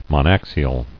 [mon·ax·i·al]